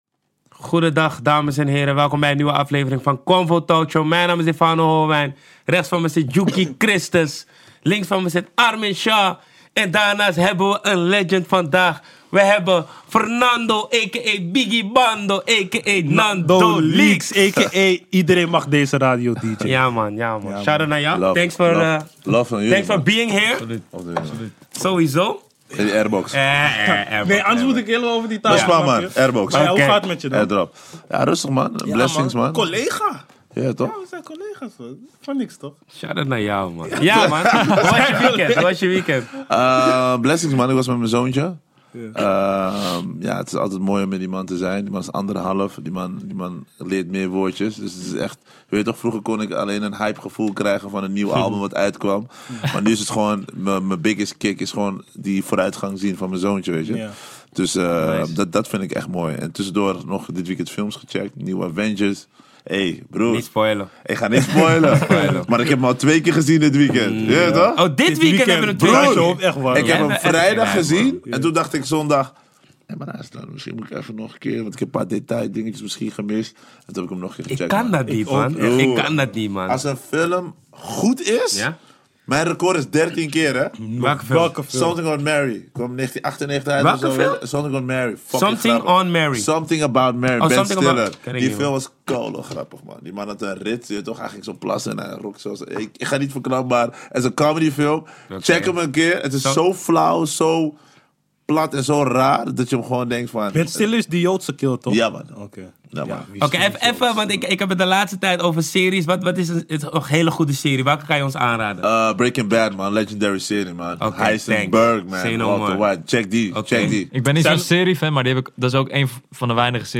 CONVO Talkshow